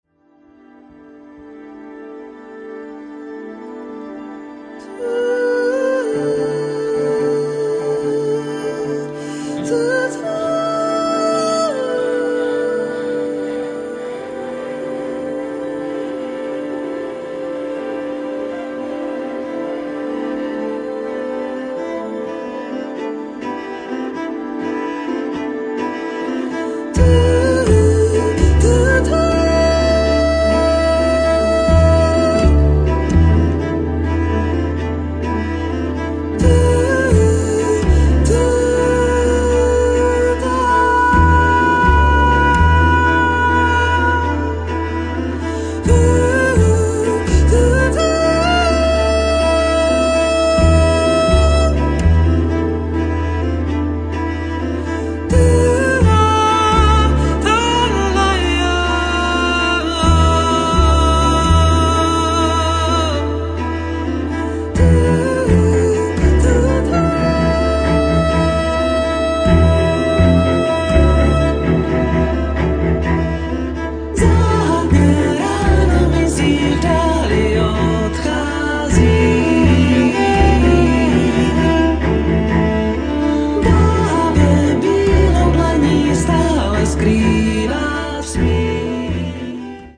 violoncello, vocals